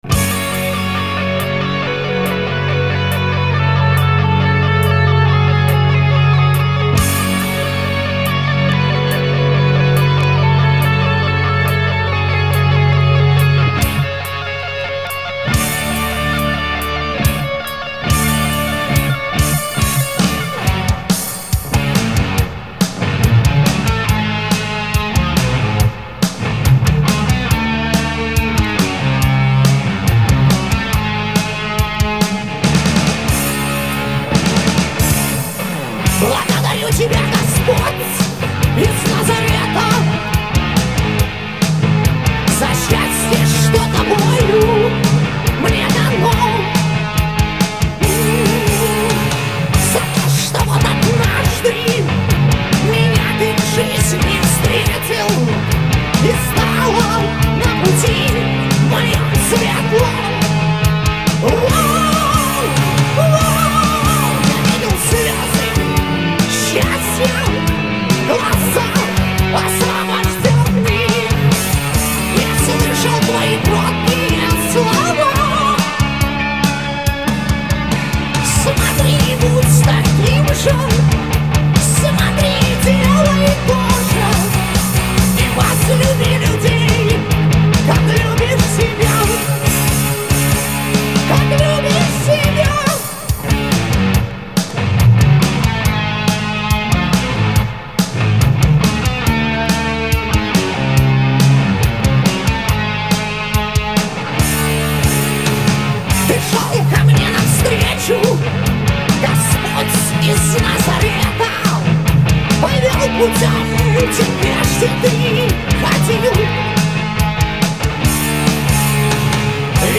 вокал
гитара
бас
барабаны
Запись студии "Диалог" (г. Николаев), 1991